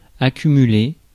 Ääntäminen
Synonyymit réunir amonceler thésauriser Ääntäminen France: IPA: [a.ky.my.le] Haettu sana löytyi näillä lähdekielillä: ranska Käännöksiä ei löytynyt valitulle kohdekielelle.